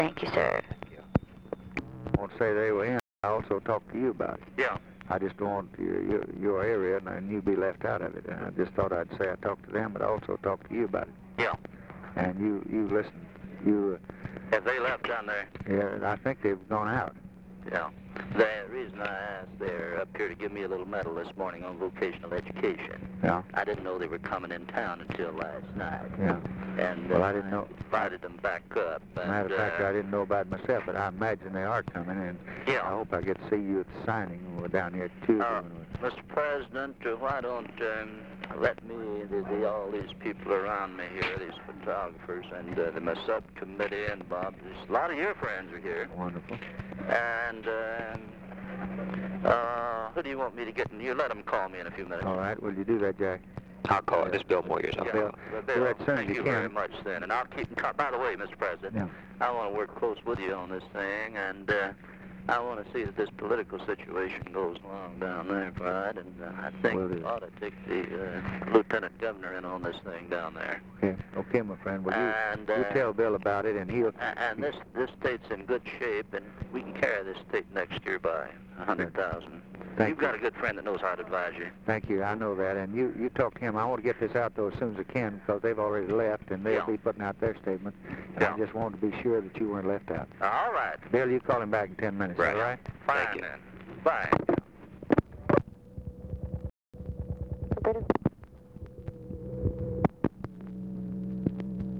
Conversation with CARL PERKINS?, December 13, 1963
Secret White House Tapes